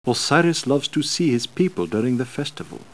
priest_osiris_e01.wav